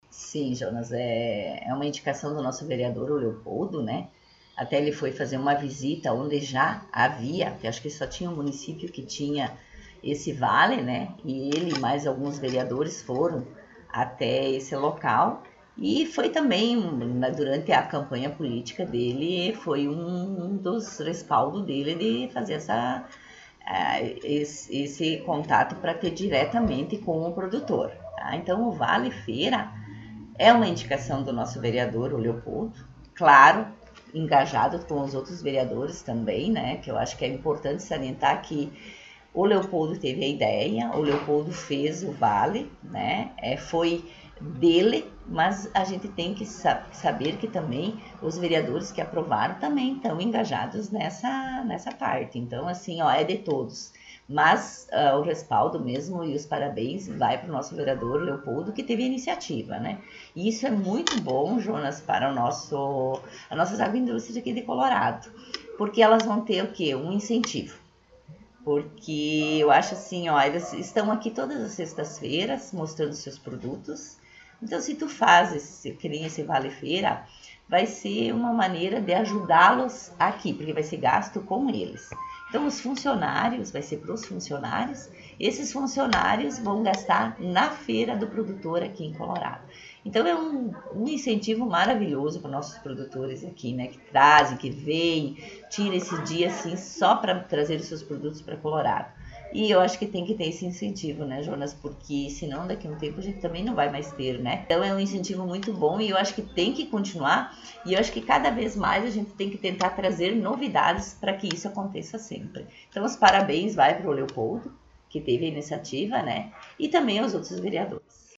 Prefeita em Exercício Marta Mino concedeu entrevista